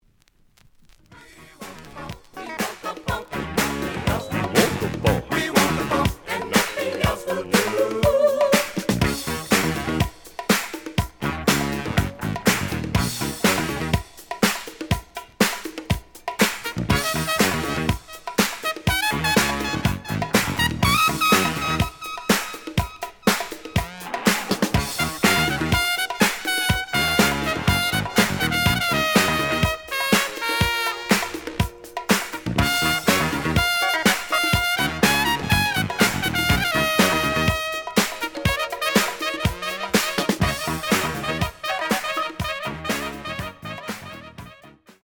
The audio sample is recorded from the actual item.
●Genre: Funk, 70's Funk
Slight edge warp.